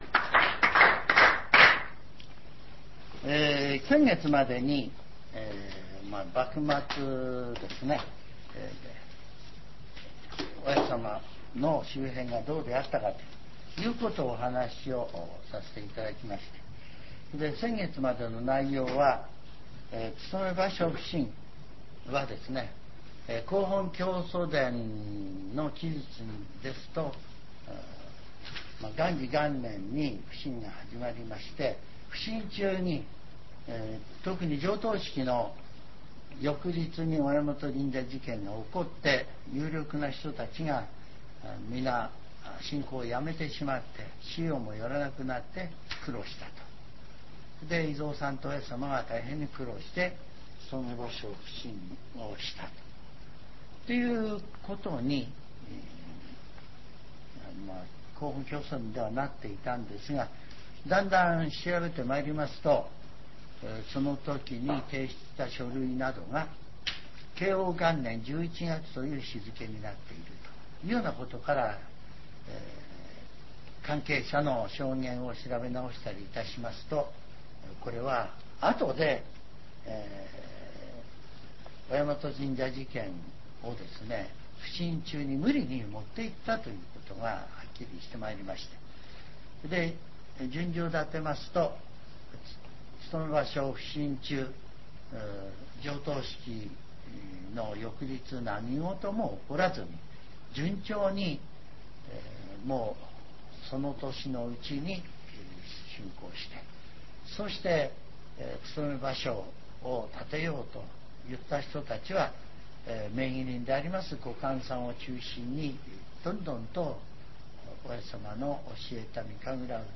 全70曲中23曲目 ジャンル: Speech